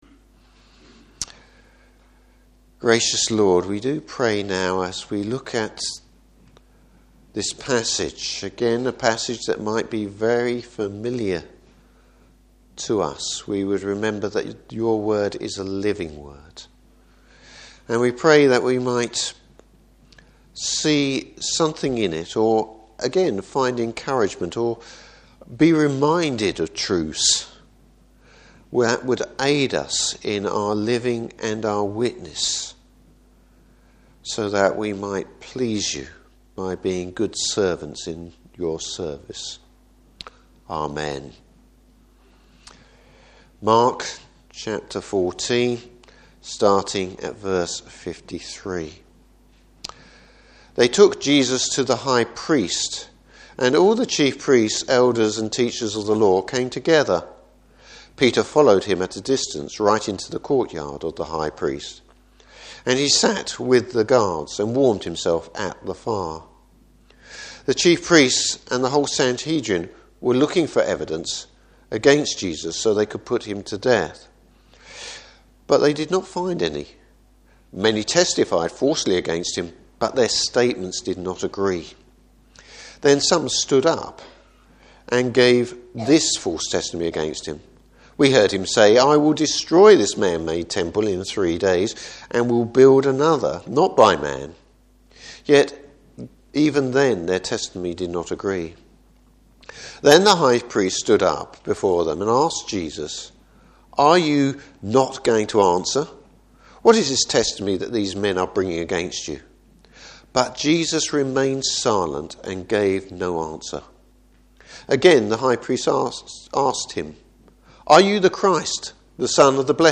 Service Type: Morning Service Jesus on trial before Jewish authorities.